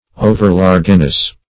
Overlargeness \O"ver*large"ness\, n. Excess of size or bulk.